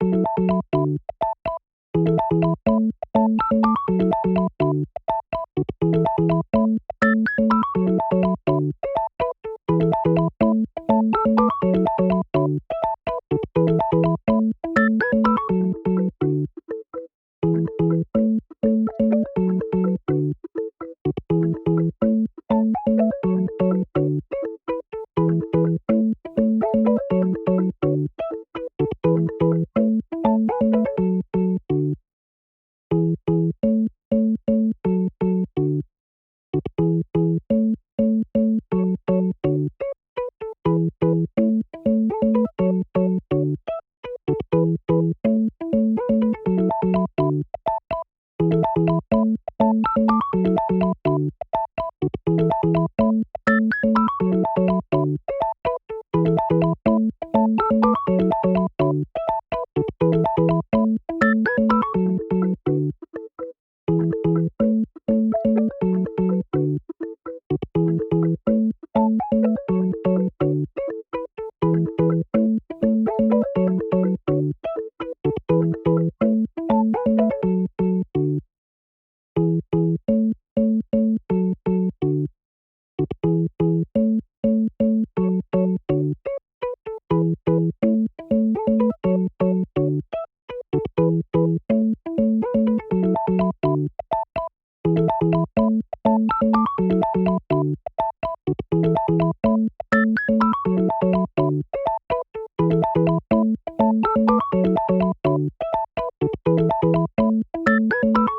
2. Hip Hop Instrumentals